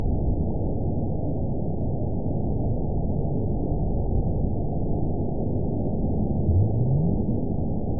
event 917801 date 04/16/23 time 23:39:23 GMT (2 years, 7 months ago) score 9.44 location TSS-AB04 detected by nrw target species NRW annotations +NRW Spectrogram: Frequency (kHz) vs. Time (s) audio not available .wav